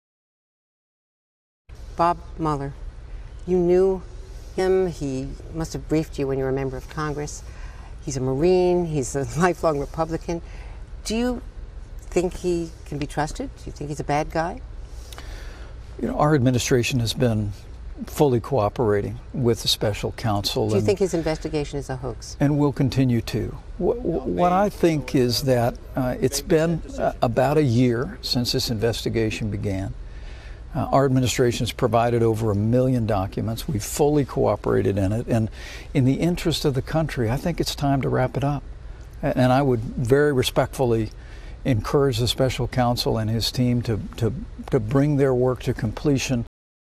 Read In Collections G. Robert Vincent Voice Library Collection Copyright Status No Copyright Date Published 2018-05-10 Interviewees Pence, Mike, 1959- Interviewers Mitchell, Andrea Broadcasters NBC Television Network Subjects Pence, Mike, 1959- United States.
Broadcast 2018 May 10